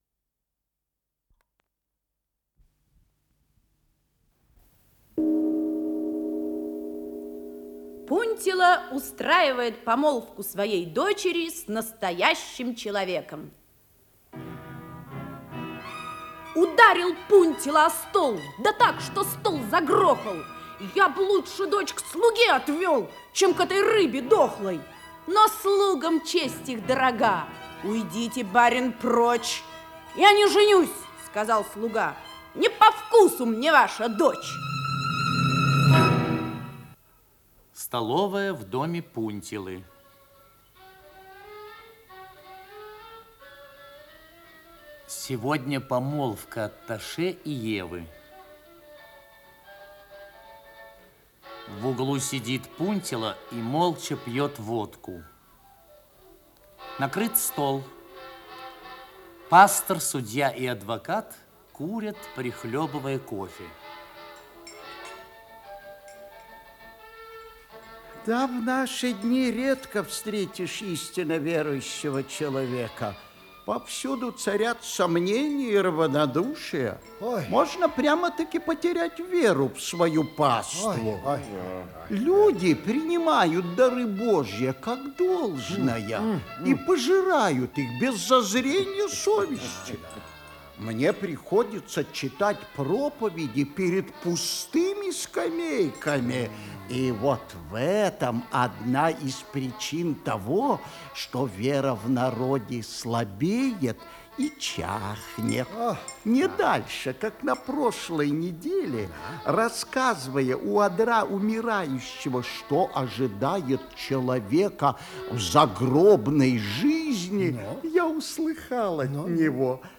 Исполнитель: Артисты ленинградских театров